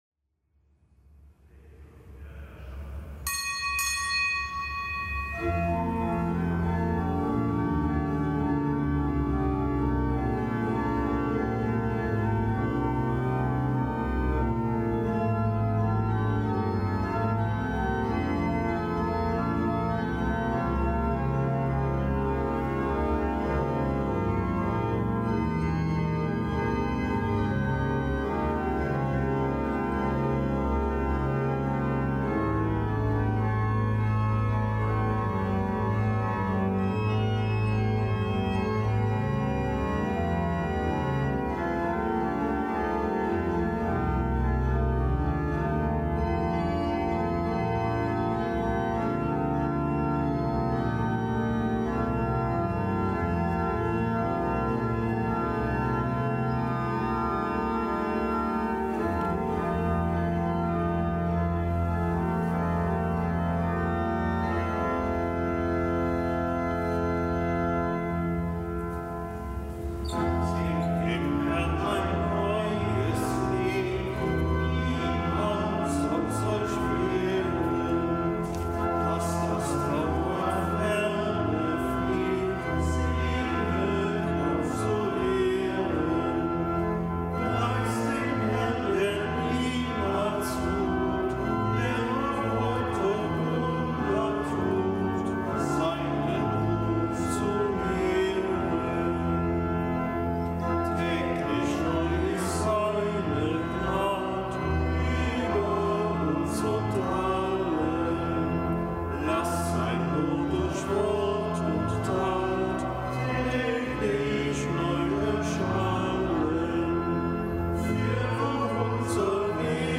Kapitelsmesse aus dem Kölner Dom am Donnerstag der siebten Woche im Jahreskreis. Dem nichtgebotenen Gedenktag des Heiligen Gregor von Narek, einem Abt und Kirchenlehrer.